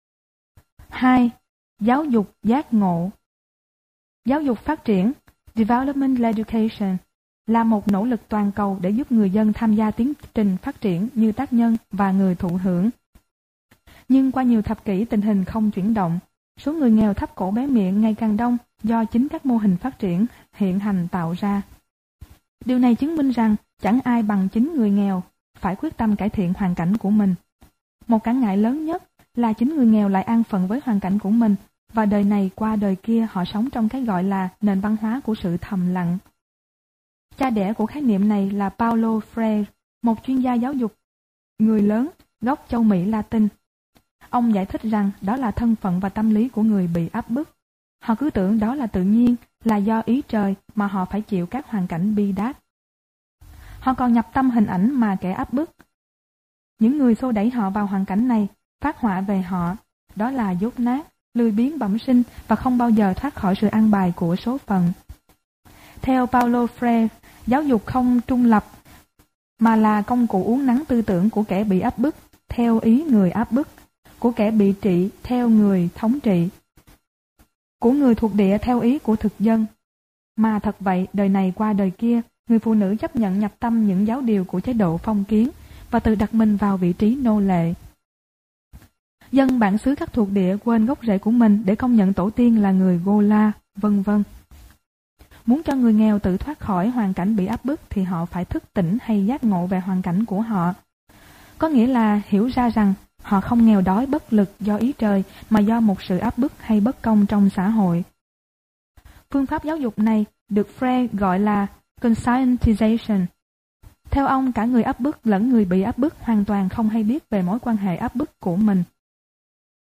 Sách nói Phát Triển Cộng Đồng - Nguyễn Thị Oanh - Sách Nói Online Hay
Phát Triển Cộng Đồng Tác giả: Nguyễn Thị Oanh Nhà xuất bản Giáo Dục Giọng đọc: nhiều người đọc